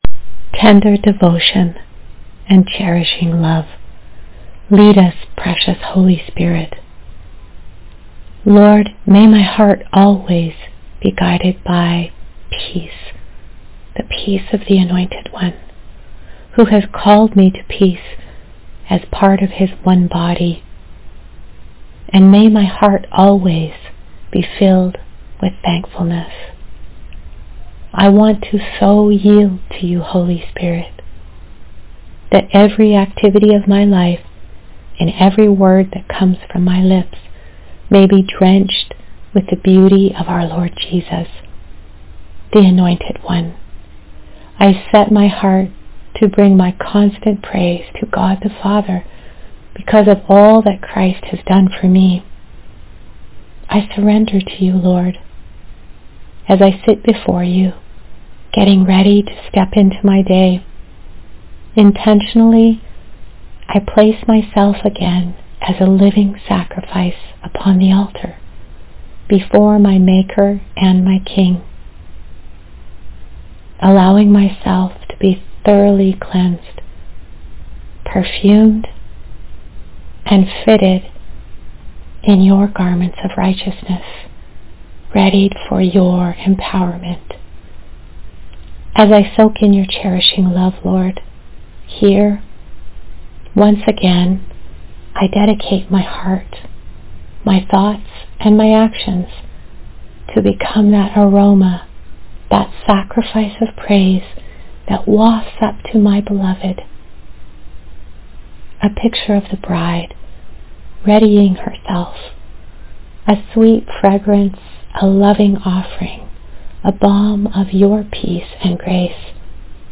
Morning Devotional Prayer